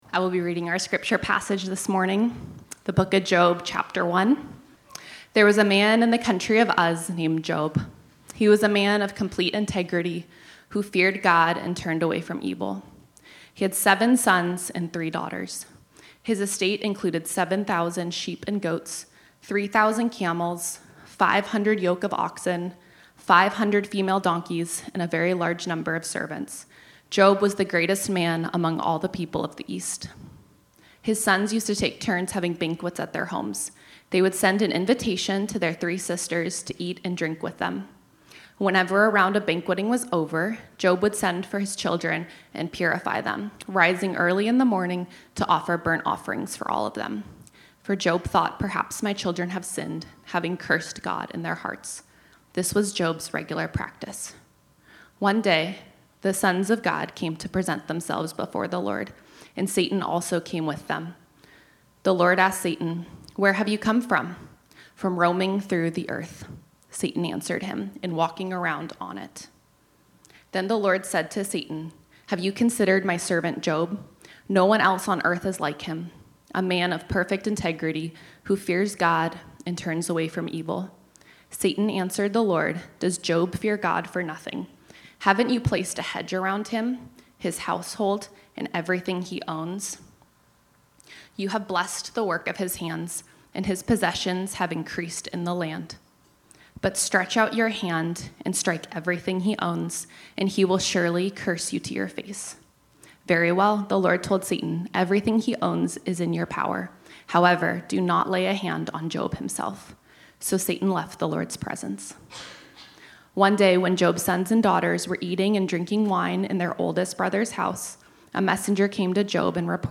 This sermon was originally preached on Sunday, January 4, 2026.